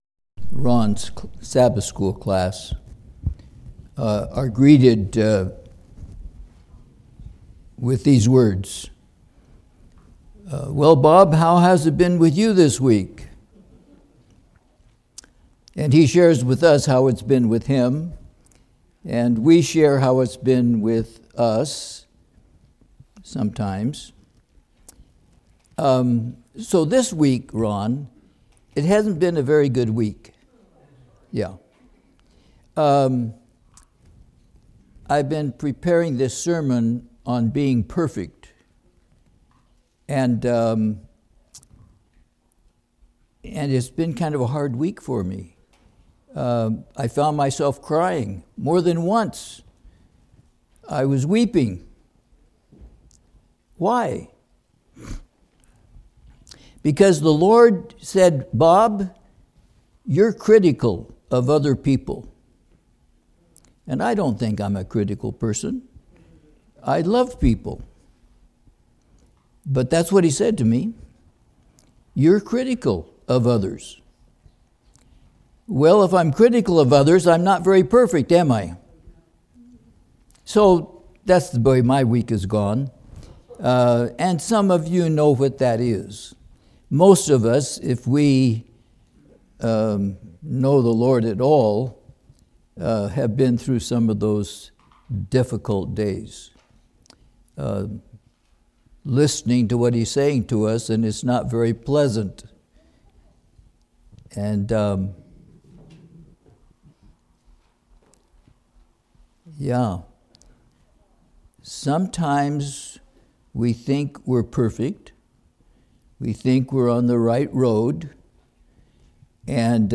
Online Sermon Audio
Download and listen to high-quality sermon audio recorded at our church.